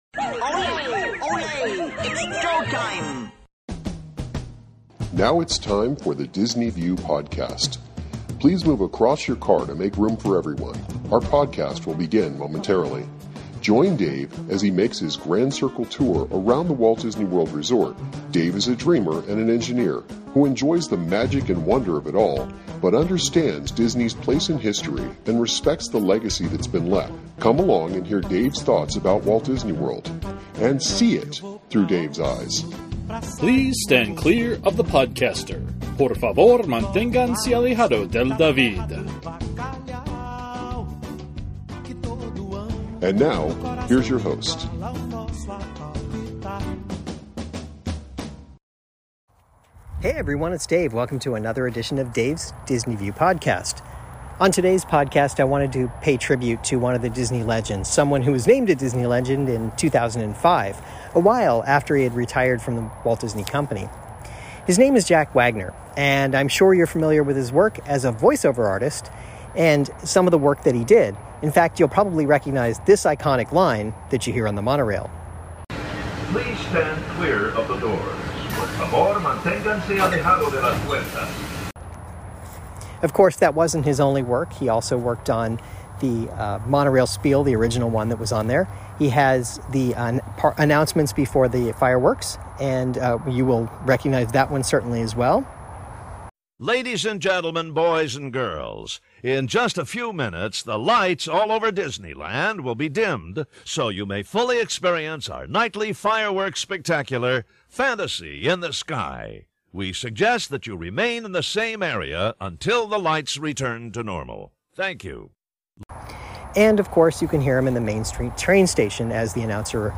I talk about him and have some audio clips of his, for you.